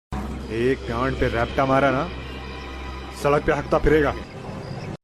ek gand pe repta mara n sarak pe hagta firega Meme Sound Effect
This sound is perfect for adding humor, surprise, or dramatic timing to your content.